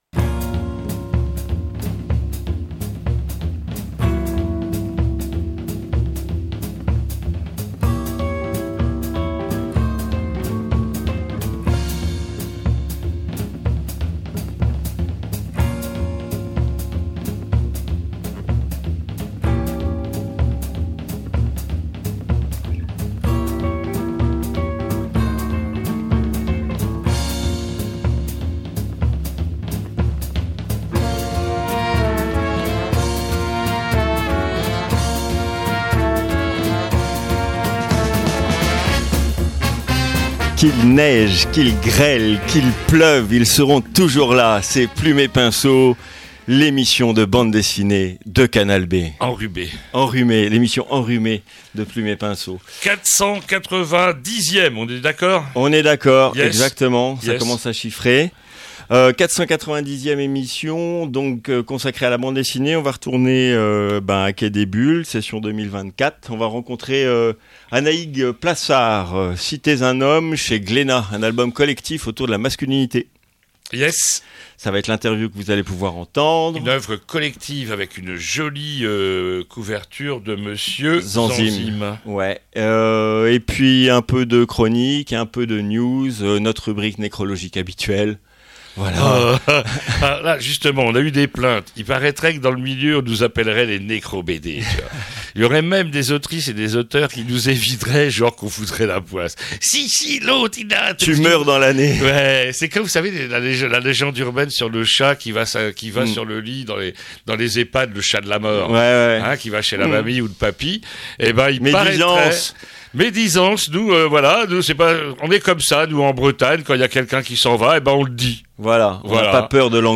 II - INTERVIEW